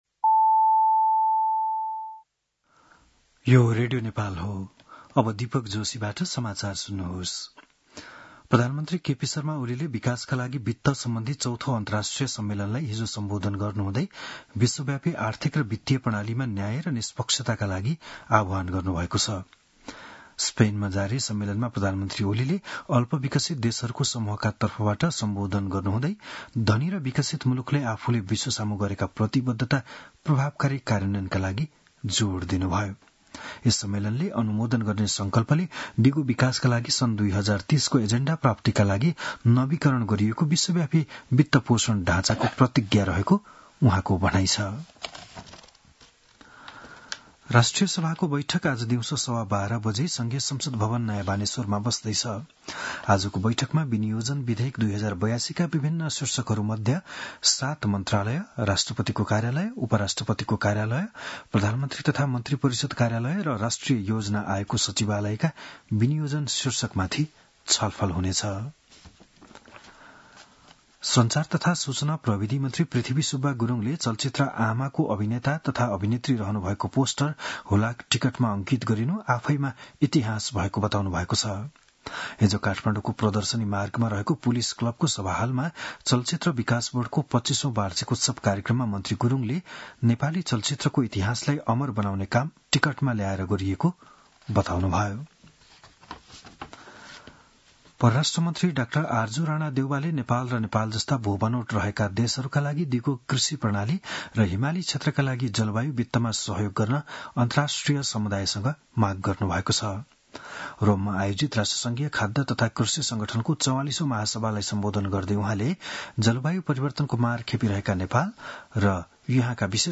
बिहान ११ बजेको नेपाली समाचार : १७ असार , २०८२